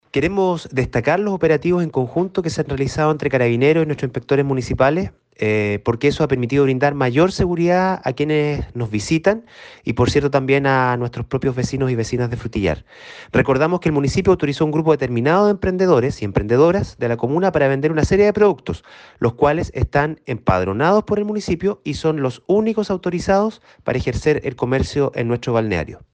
En tanto, el Alcalde Subrogante de la comuna de Frutillar, Víctor Naíl, destacó el trabajo conjunto de Carabineros e inspectores municipales con el fin de dar seguridad a quienes visitan el balneario de Frutillar Bajo, lo cual- a su vez- permite controlar el comercio ilegal en la comuna.